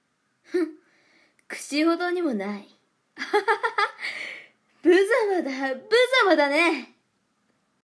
サンプルボイス 病み、恍惚 【少年】